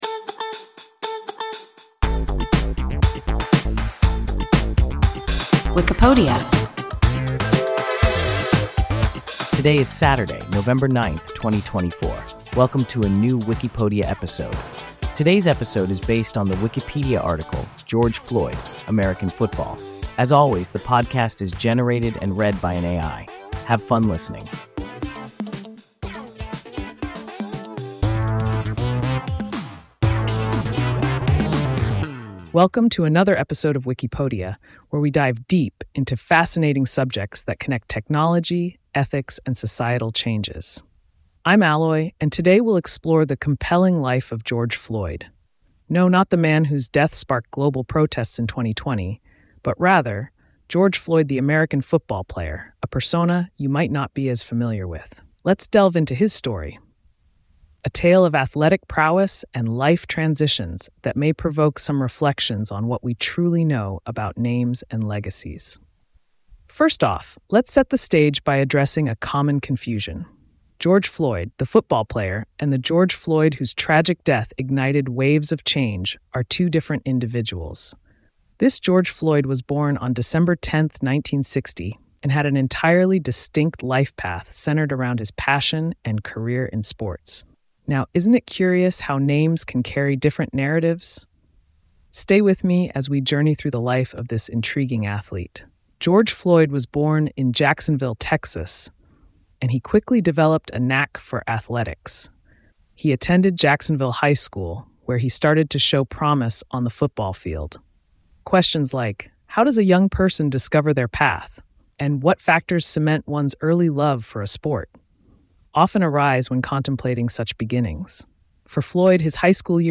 George Floyd (American football) – WIKIPODIA – ein KI Podcast